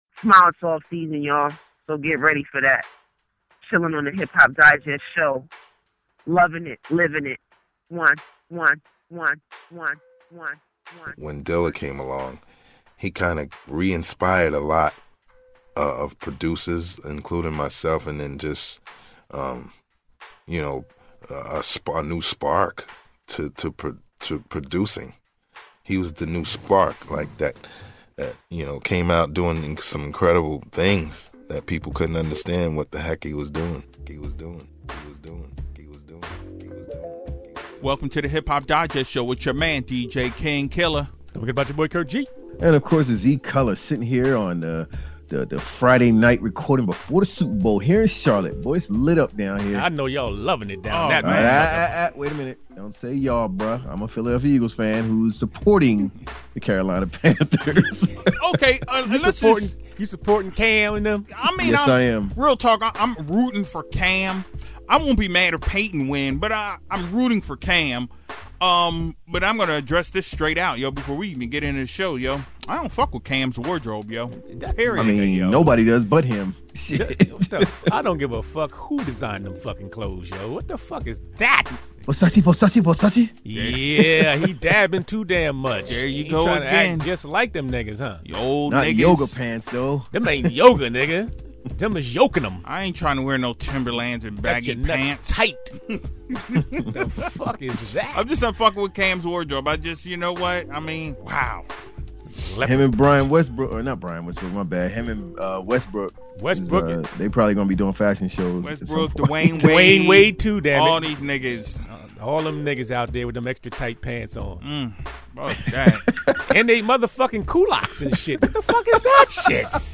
Another week of real Hip Hop talk from your boys! Calmer heads have prevailed, no big rants this week, but as always we touch on topics and keep it all the way real.